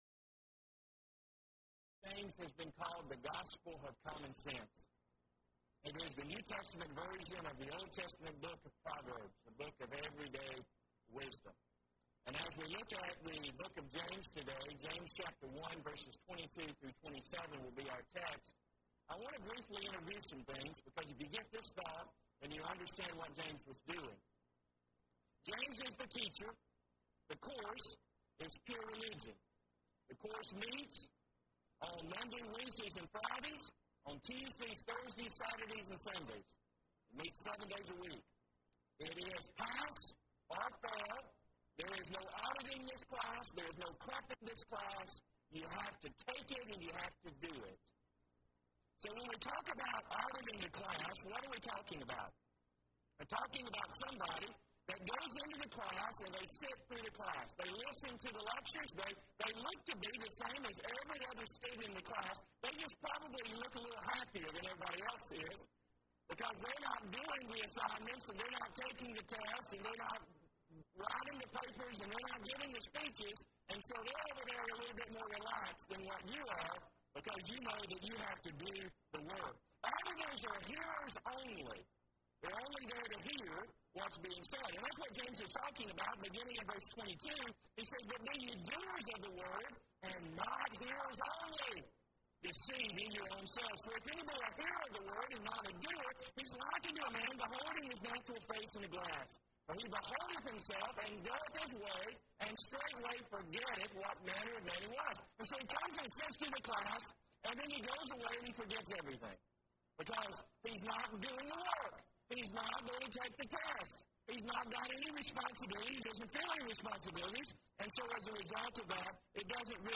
Event: 2nd Annual Young Men's Development Conference
lecture